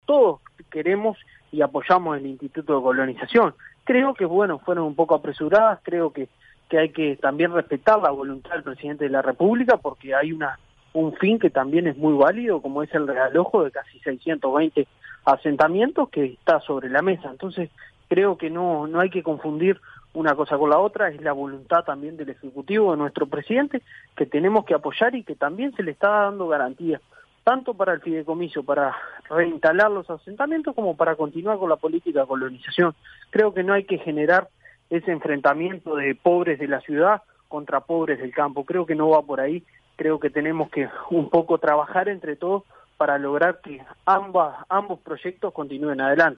El diputado del Partido Nacional Álvaro Rodríguez en entrevista con 970 Noticias Edición Central le respondió al senador nacionalista Botana y dijo que no hay que generar el enfrentamiento de pobres de la ciudad contra pobres del campo, «no va por ahí».